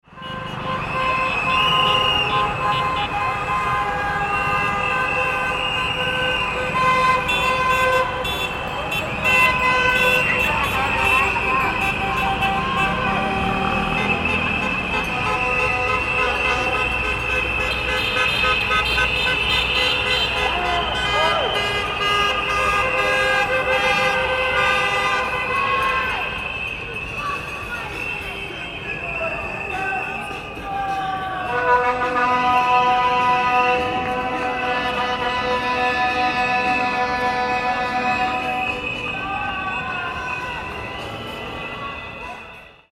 Loud And Chaotic Street Ambience for Filmmakers
Loud And Chaotic Street Protest Sound Effect
Crowds scream, shout, and create chaos in a busy city street. Cars honk and add to the noisy urban environment. Perfect street protest sound effect for films, videos, and game scenes needing intense ambience.
Loud-and-chaotic-street-protest-sound-effect.mp3